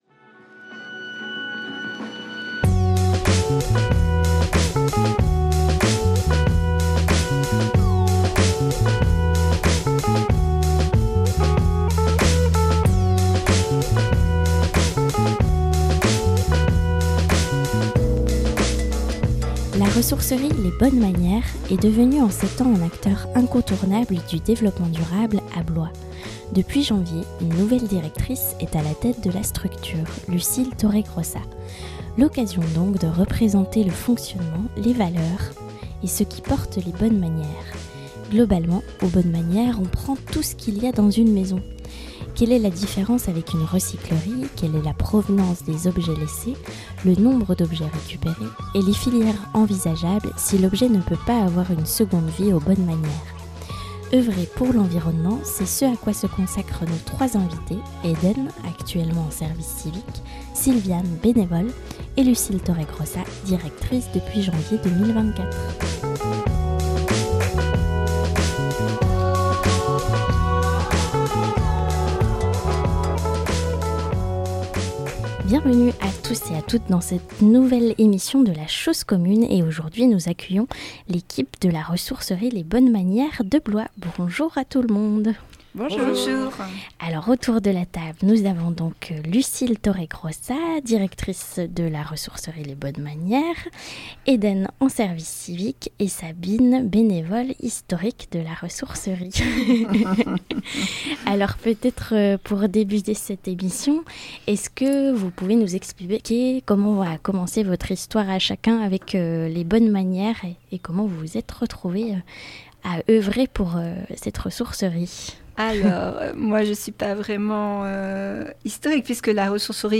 Œuvrer pour l’environnement, c’est ce à quoi ce consacrent nos trois invités